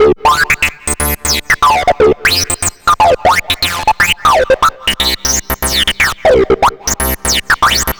Session 14 - Acid Lead.wav